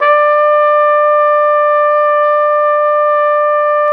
Index of /90_sSampleCDs/Roland L-CD702/VOL-2/BRS_Flugelhorn/BRS_Flugelhorn 2